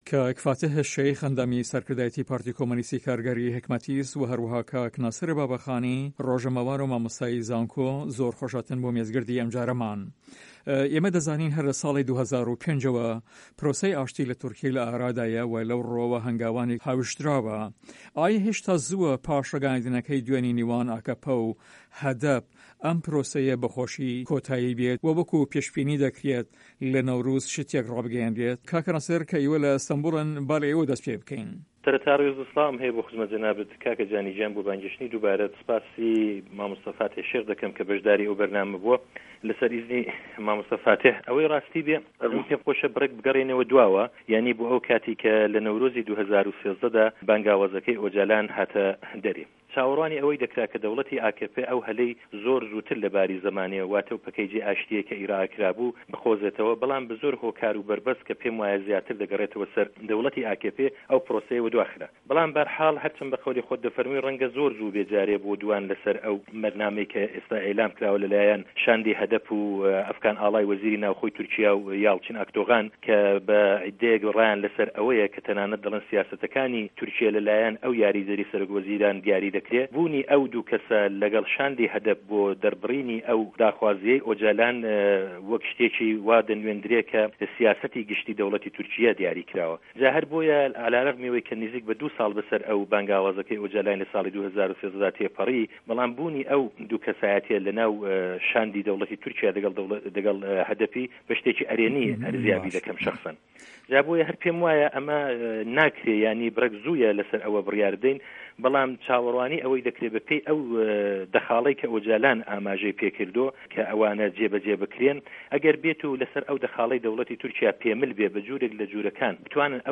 مێز گرد:ئاشتی، چه‌ک دانان یان ئاگر به‌س. PKK.HDP.AKP قه‌ندیل.